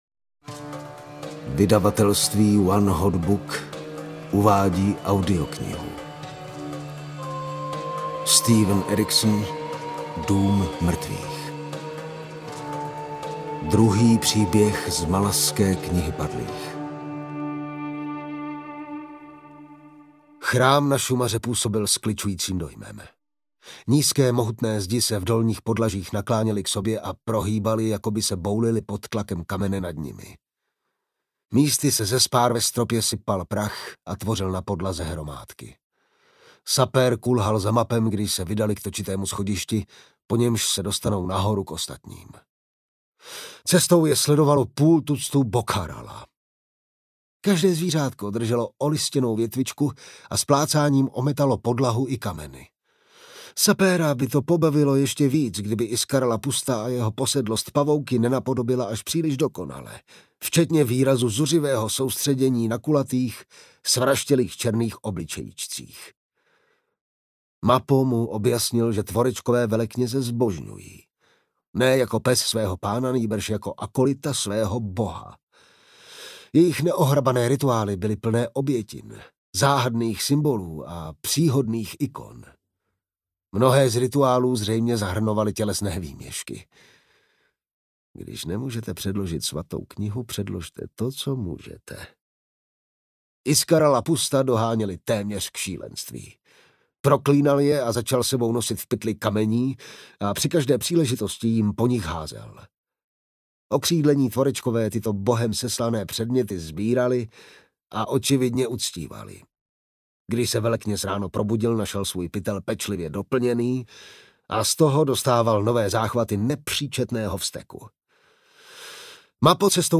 Dům mrtvých audiokniha
Ukázka z knihy
dum-mrtvych-audiokniha